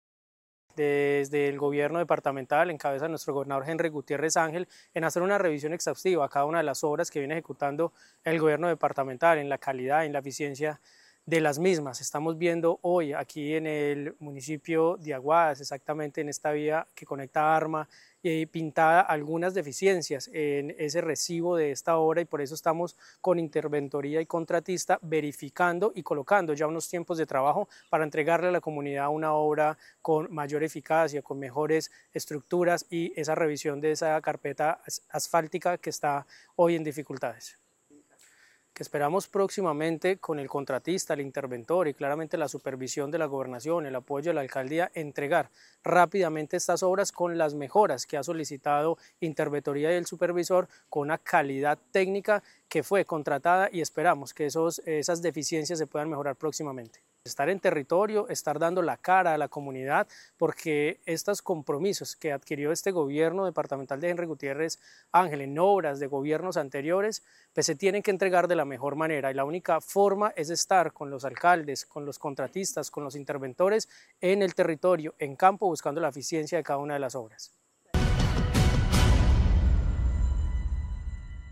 Gobernador encargado, Ronald Bonilla.